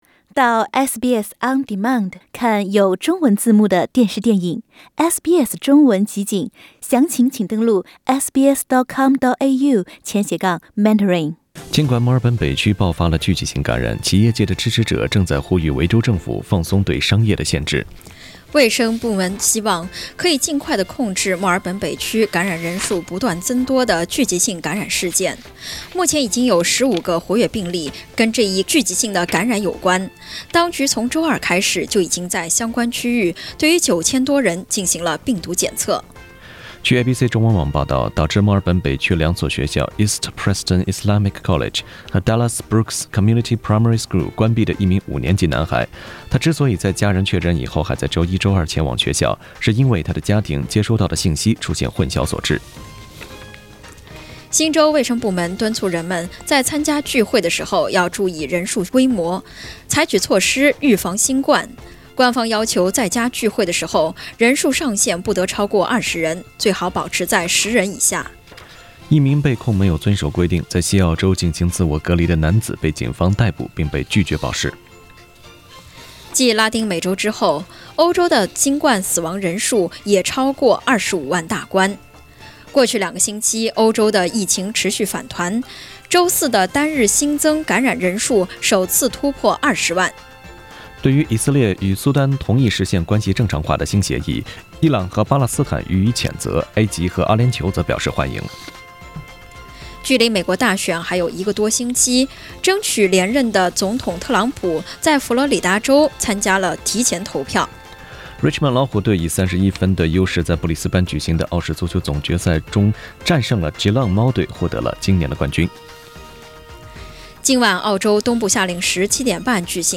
SBS早新聞（10月25日）